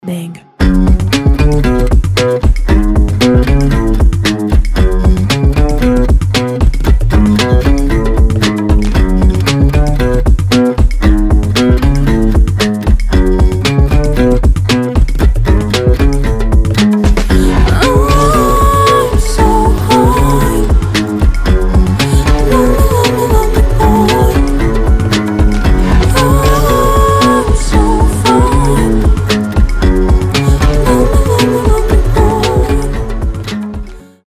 поп
гитара
женский вокал
чувственные
вживую